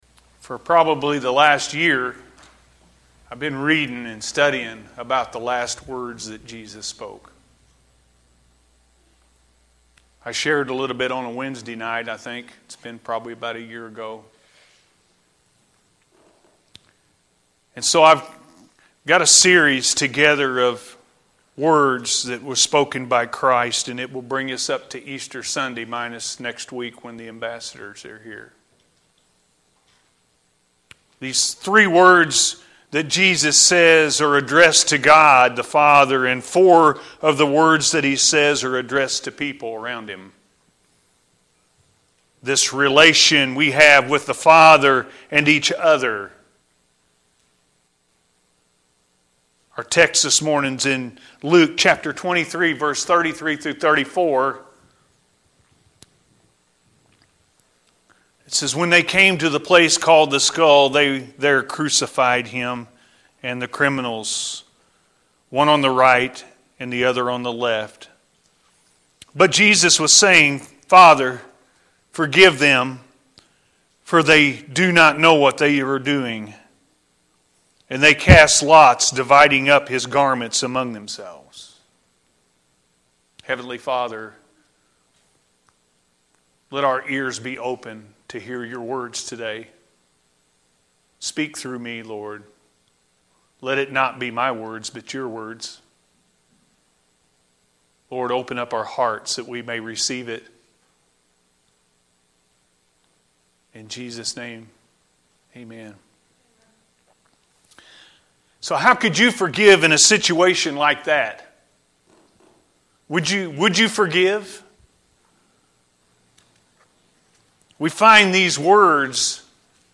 Father, Forgive Them-A.M. Service – Anna First Church of the Nazarene